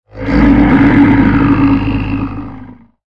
恐怖 " 迪吉里杜管长调
描述：长的迪吉里杜管色调，适合样品
标签： 仪表 迪吉里杜管
声道立体声